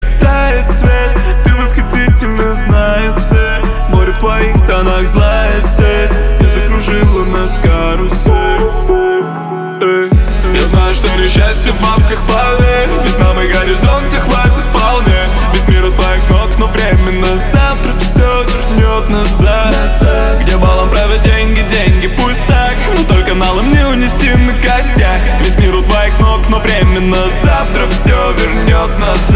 Хип-хоп
русский рэп